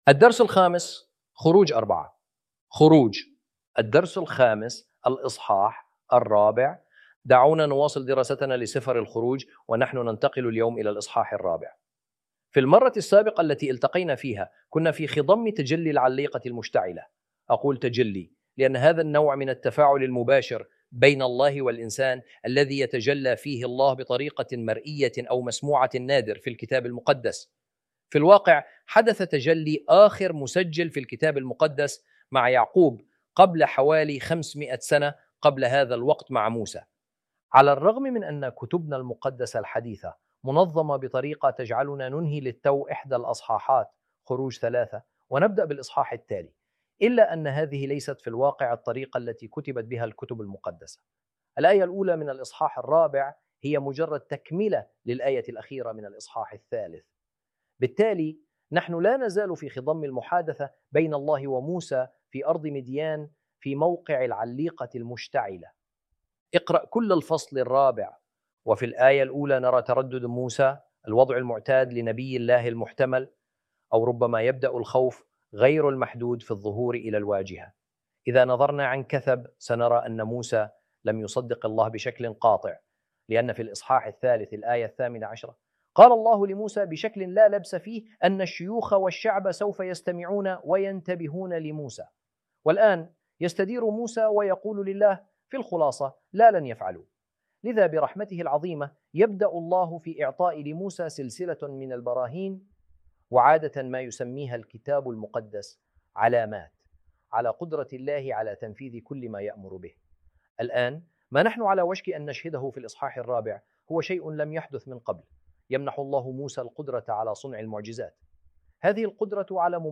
ar-audio-exodus-lesson-5-ch4.mp3